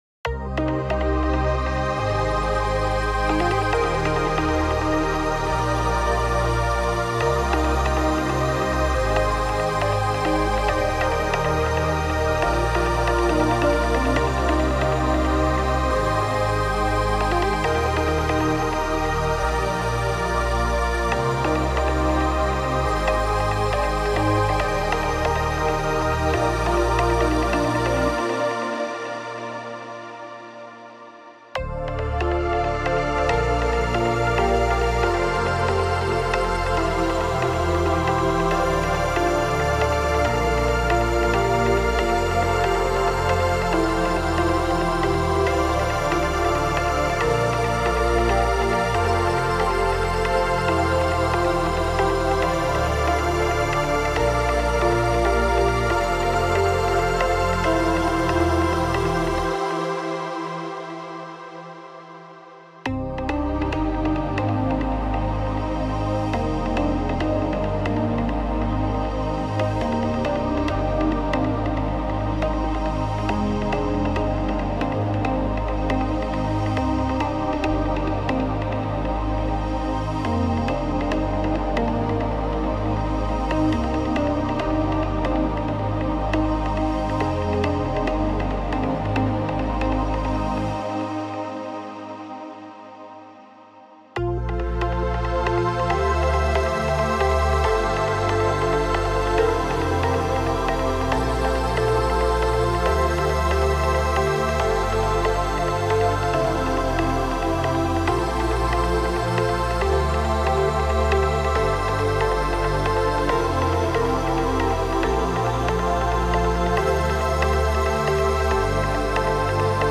Trance Uplifting Trance
10 x Pad
10 x Pluck
10 x Bass Line
(Preview demo is 138 BPM)
Style: Trance, Uplifting Trance